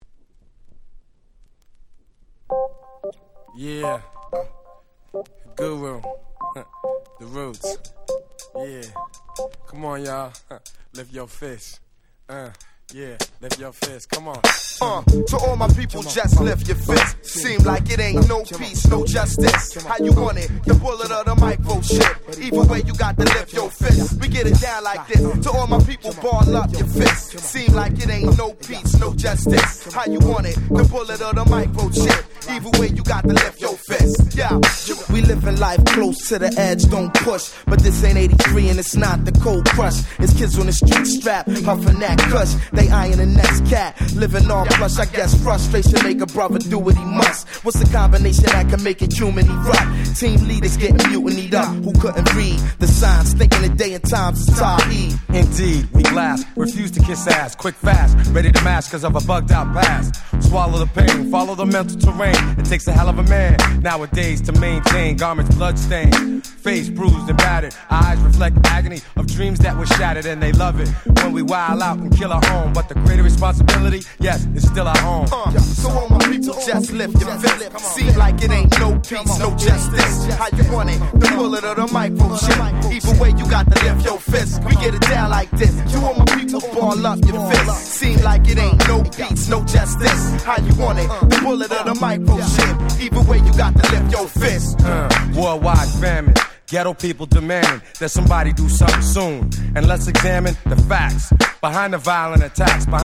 00' Very Nice Hip Hop !!